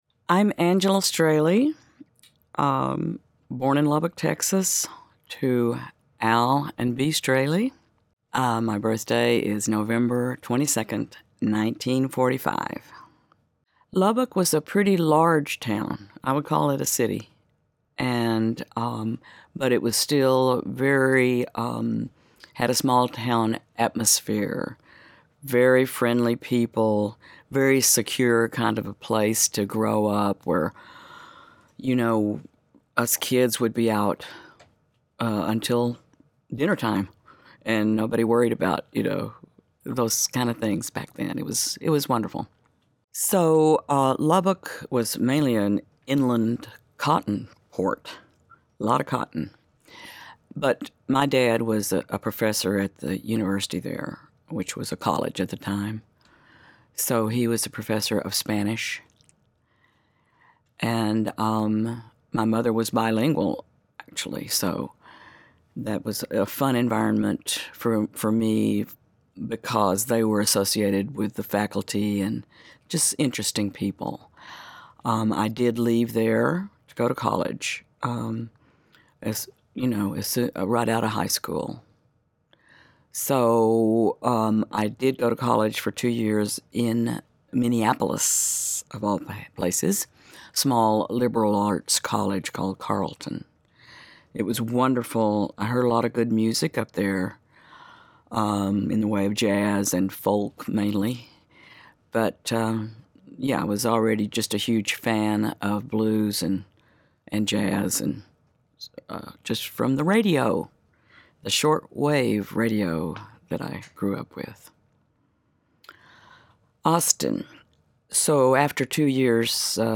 A 32 minute interview with vocalist Angela Strehli who was one of the leading blues musicians in Austin and an important figure in the early days of Antone's, Austin's internationally famous Blues club.
Interview with Angela Strehli